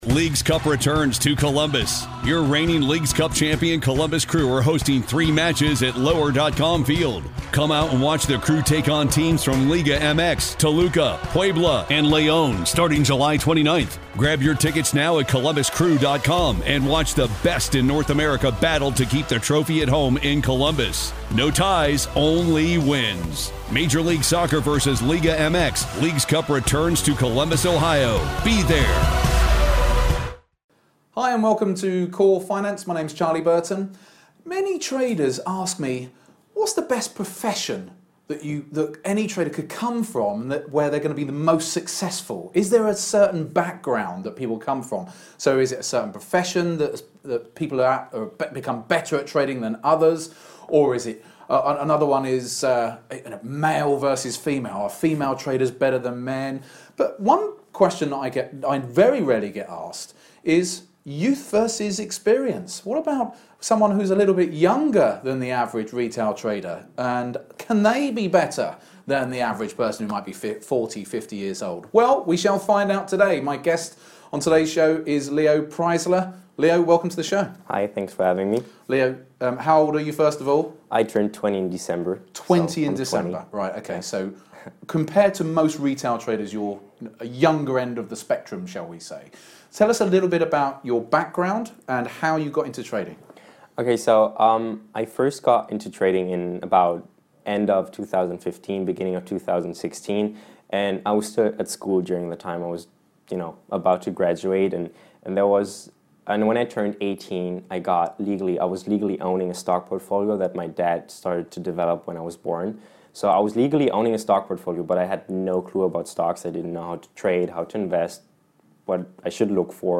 In a dynamic and revealing interview